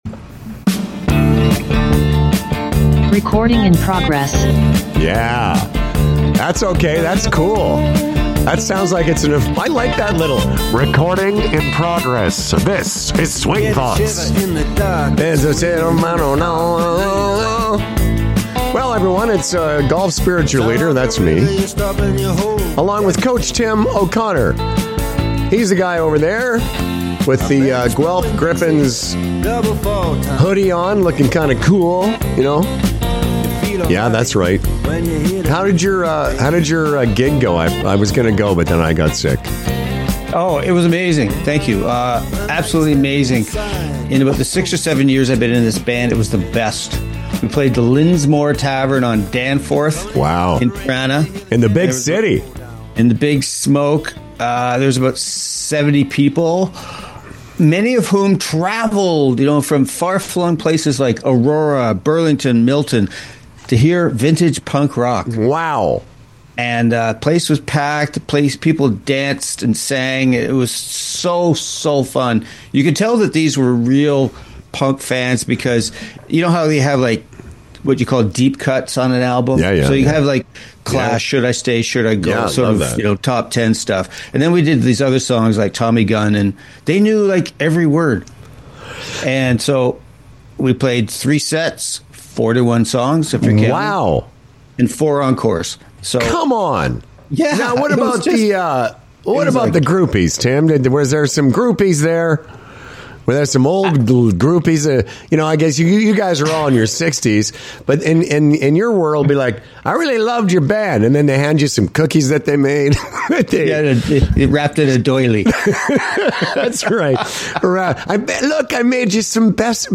… continue reading 306 episoder # Sports # Stories # Interviews # Golf Stories # Golf Interviews # Golf # The Sonar Network # Swinging